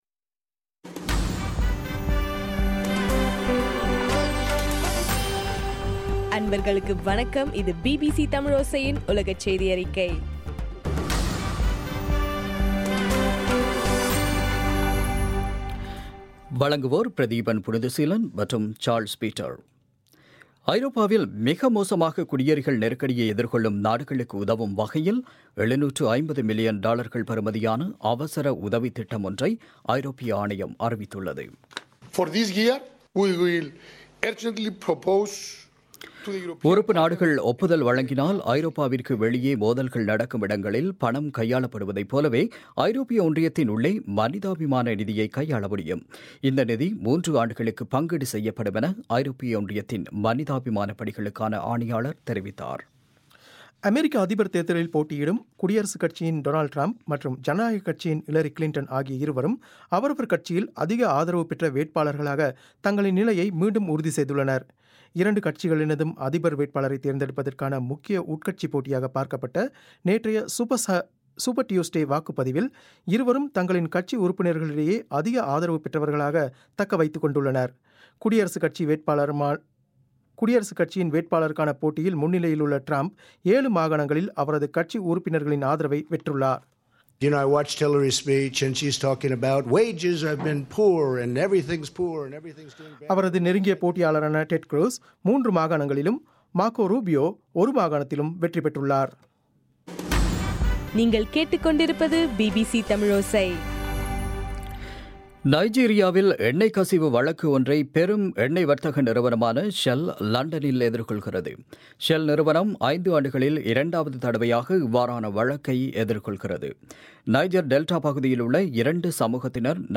இன்றைய (மார்ச் 2) பிபிசி செய்தியறிக்கை